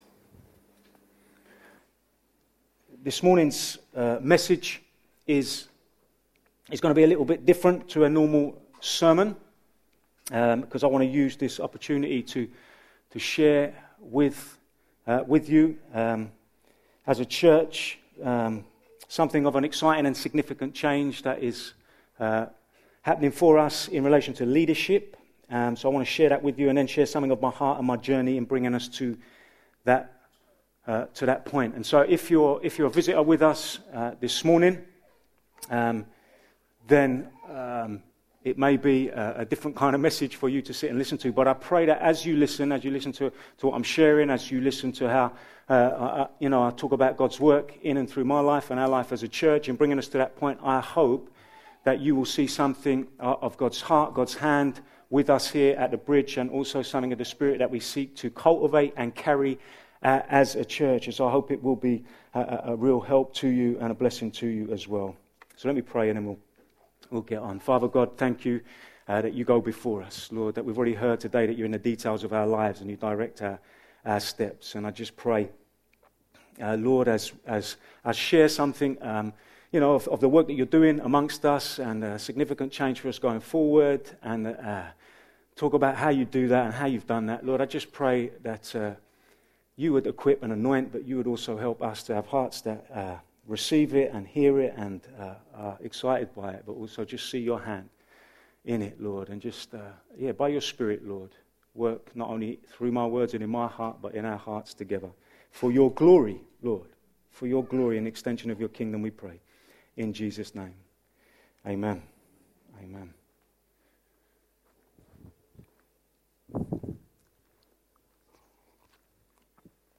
A message from the series "Healthy Church." In this message we outline a significant leadership change for the church, and then unpack something of the journey that has led to a change in belief regarding women in leadership, and the need to release both men and women to be all that God has gifted and called them to be in the church.